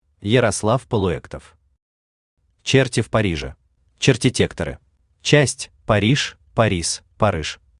Aудиокнига Черти в Париже Автор Ярослав Полуэктов Читает аудиокнигу Авточтец ЛитРес.